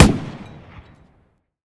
tank-mg-shot-7.ogg